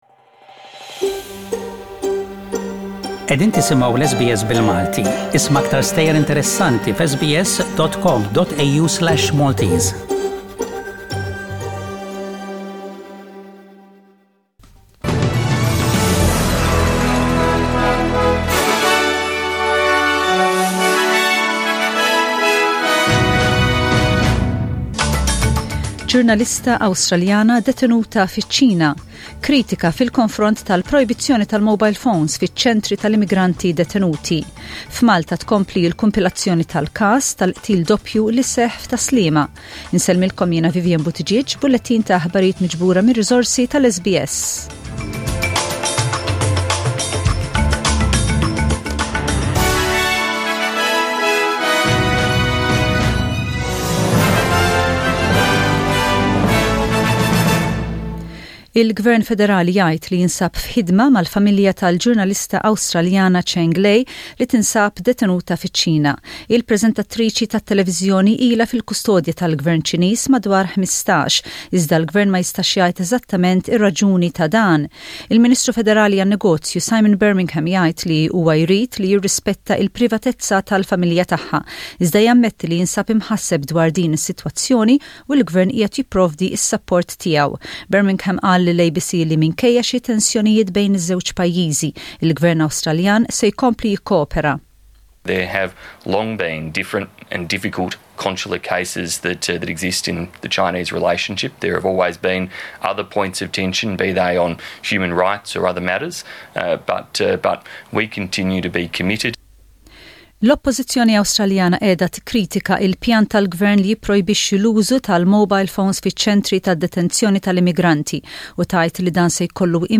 SBS Radio | News in Maltese: 01/09/20